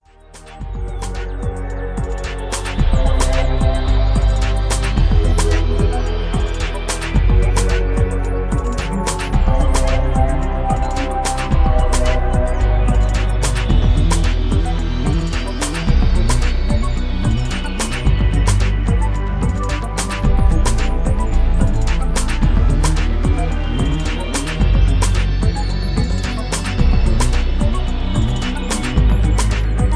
Electro Ambient Loop with feel of Mystery